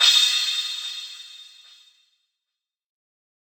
crash 3.wav